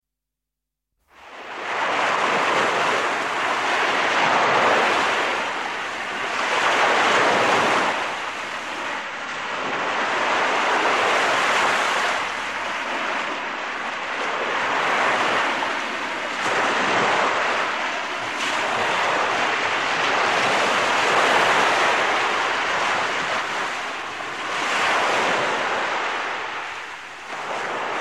Relaxing melody with a breath of summer